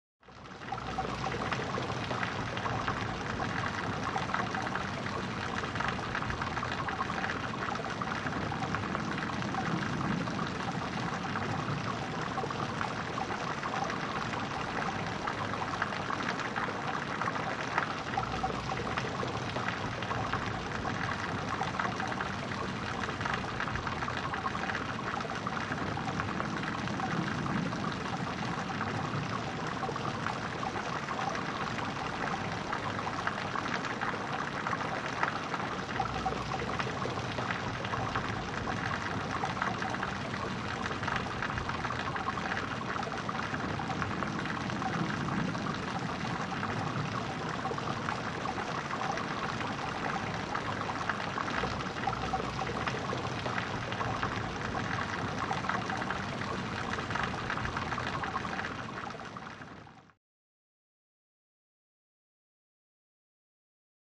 Rain, Running Out Of Drainage Pipe Into Gutter.